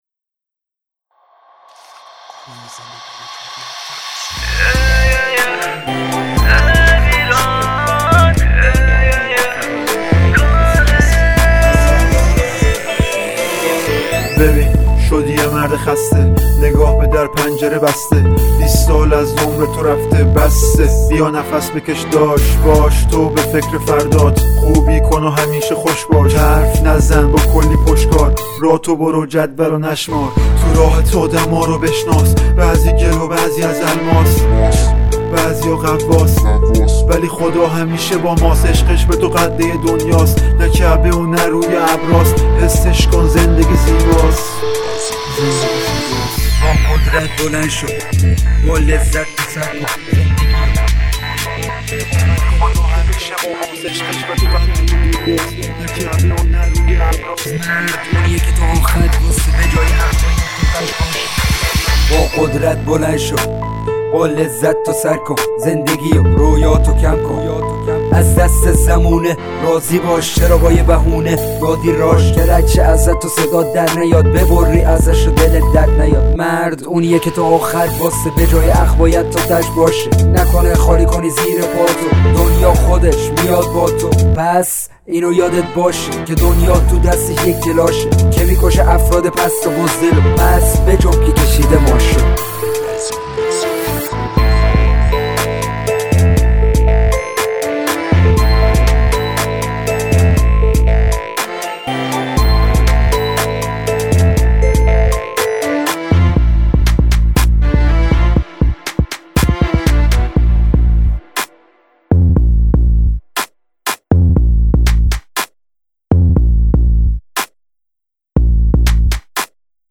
به سبک رپ اجتماعی اجرا شده است